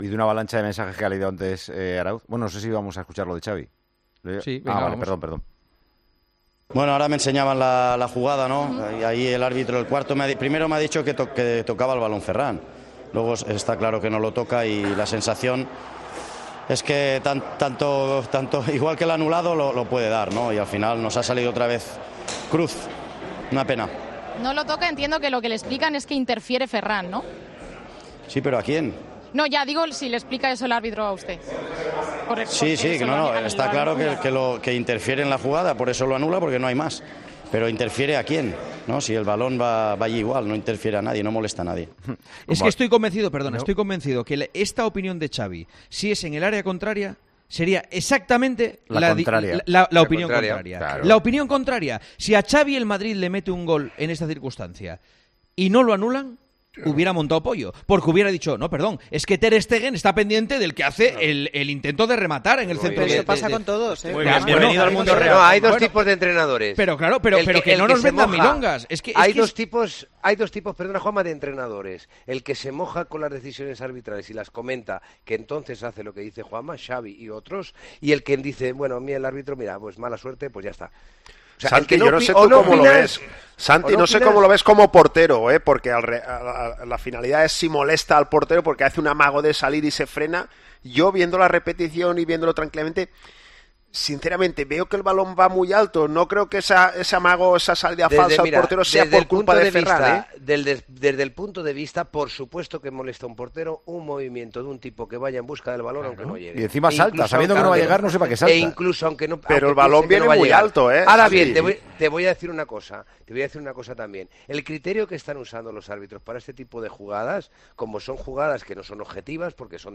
Escuchadas las declaraciones de Xavi Hernández, llegaba el momento de analizar la jugada en El Tertulión de Tiempo de Juego de este domingo, con Juanma Castaño al frente.